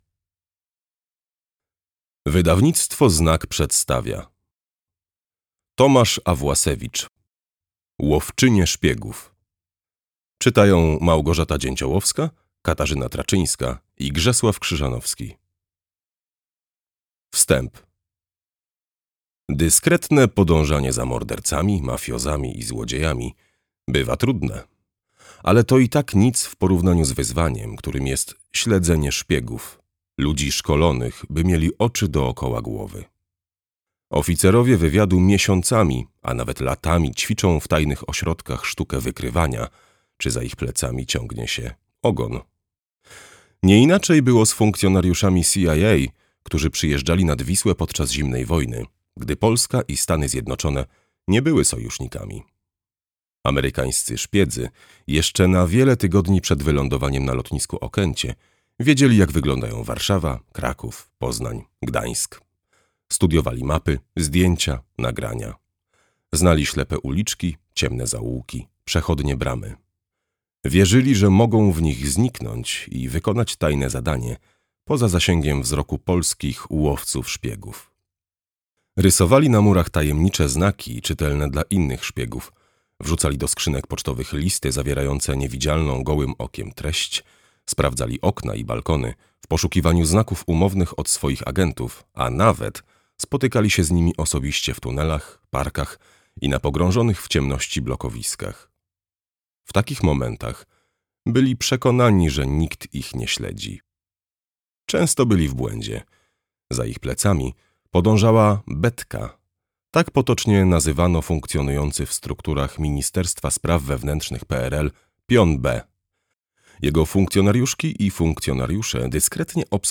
Łowczynie szpiegów - Tomasz Awłasewicz - audiobook